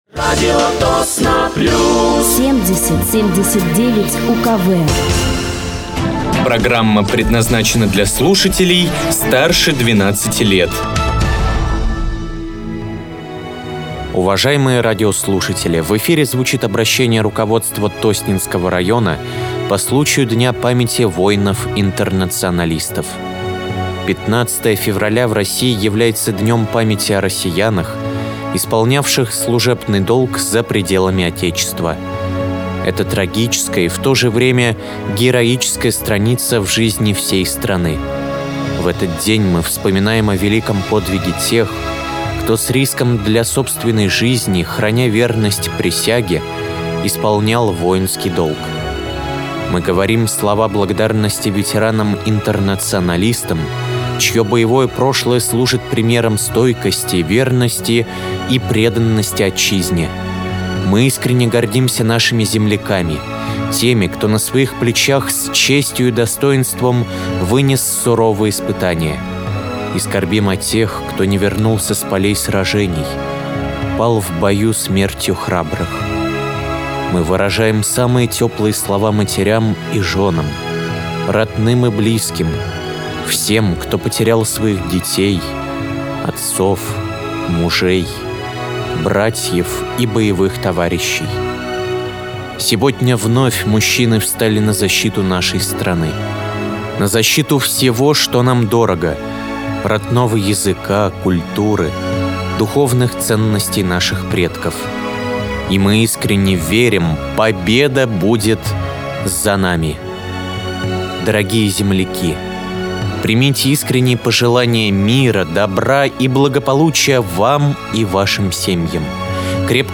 Обращение руководства района по случаю Дня памяти воинов-интернационалистов
Глава Тосненского муниципального района А.Л. Канцерев и глава администрации района М.И. Носов